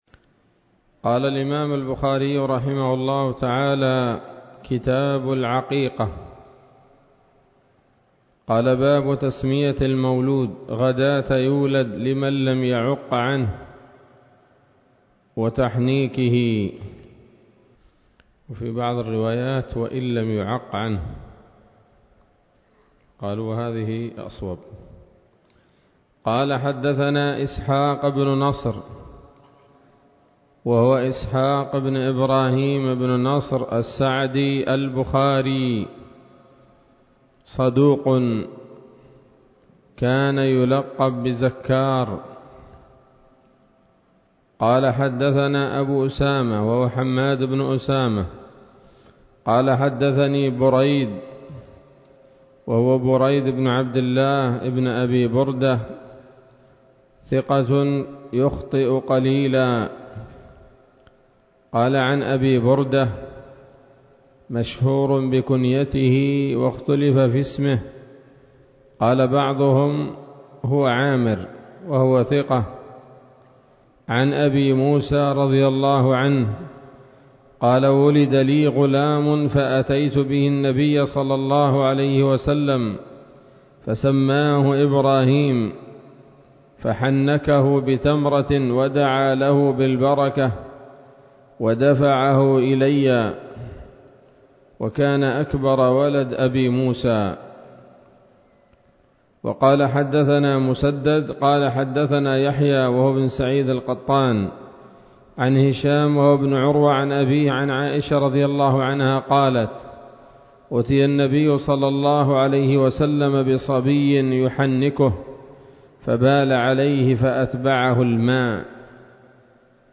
الدرس الأول من كتاب العقيقة من صحيح الإمام البخاري